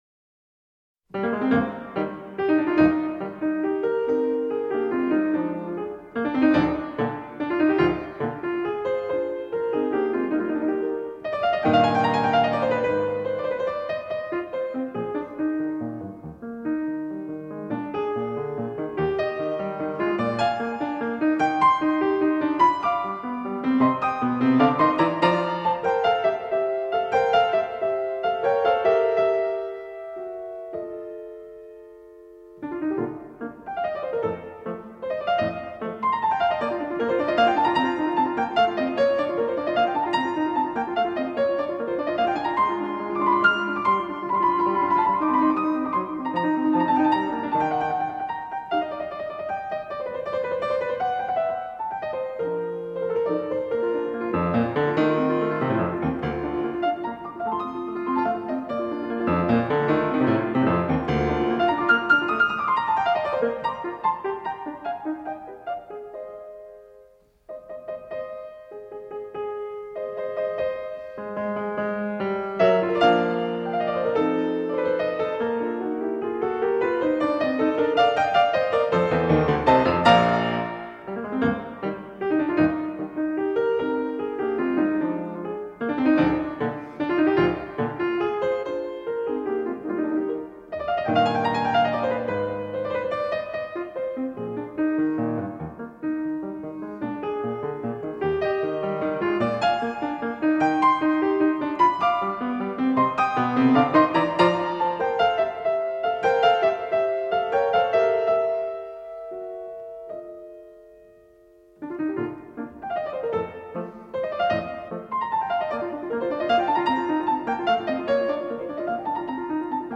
Соната № 49  Ми Ь мажор  (1 часть)
1_allegro.mp3